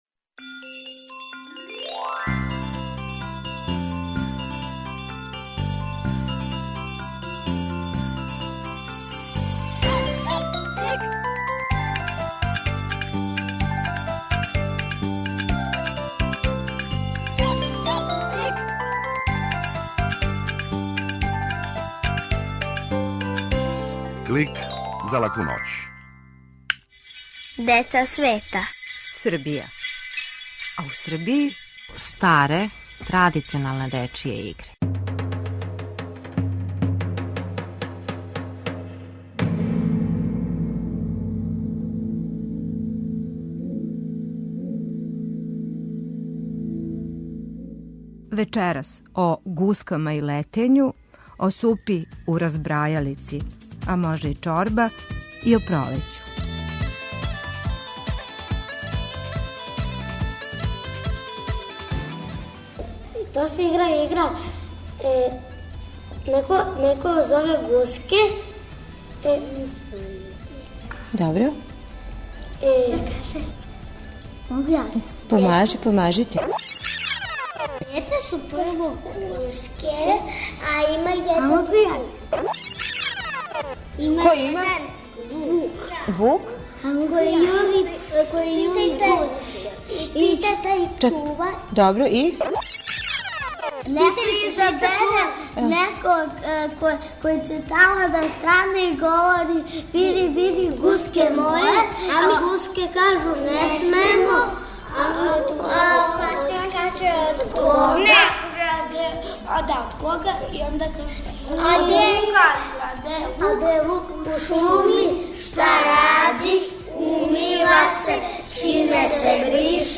Клик је кратка емисија за децу, забавног и едукативног садржаја. Сваке седмице наши најмлађи могу чути причу о деци света, причу из шуме, музичку упознавалицу, митолошки лексикон и азбуку звука. Уколико желите да Клик снимите на CD или рачунар, једном недељно,на овој локацији можете пронаћи компилацију емисија из претходне недеље, које су одвојене кратким паузама.